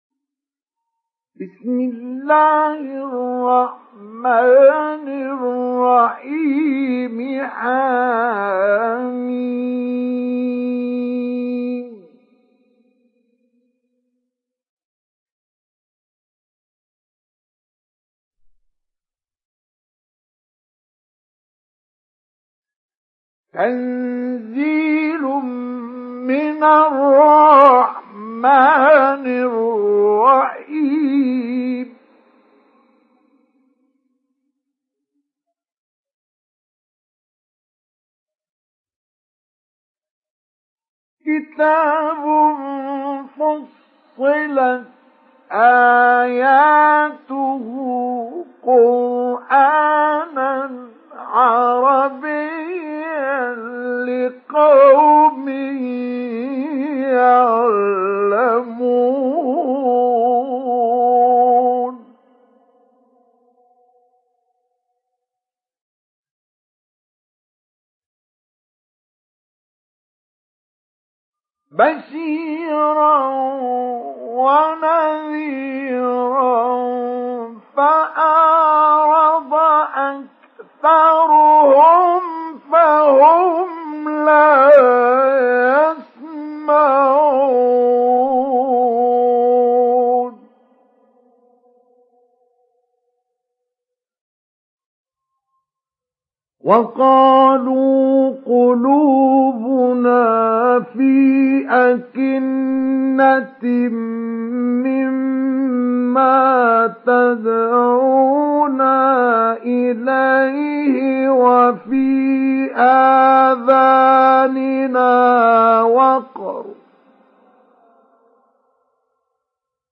دانلود سوره فصلت mp3 مصطفى إسماعيل مجود روایت حفص از عاصم, قرآن را دانلود کنید و گوش کن mp3 ، لینک مستقیم کامل
دانلود سوره فصلت مصطفى إسماعيل مجود